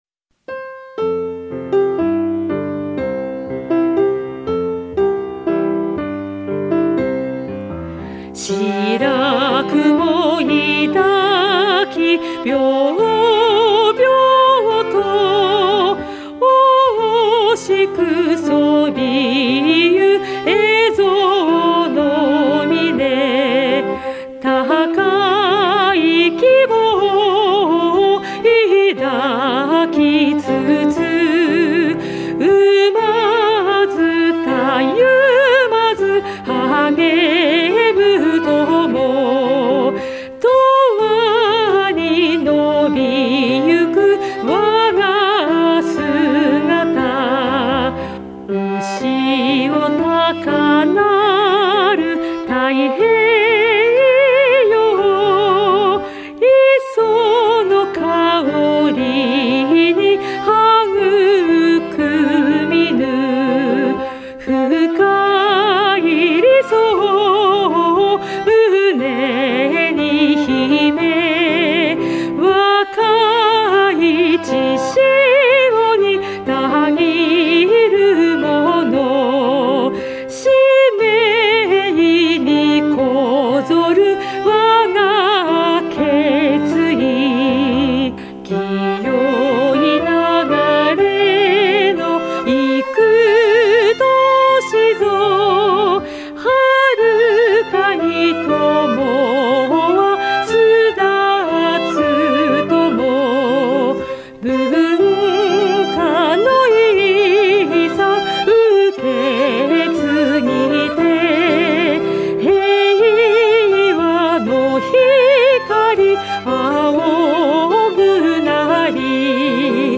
笛舞小学校校歌【歌：アンサンブルグループ奏楽（そら）】
笛舞小校歌.mp3